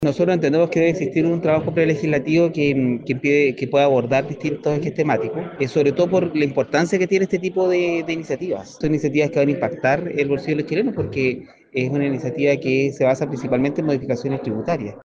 El jefe de la bancada de dicha tienda política, Jorge Díaz, destacó el trabajo prelegislativo y apuntó a la importancia de alcanzar acuerdos antes de discutir la iniciativa.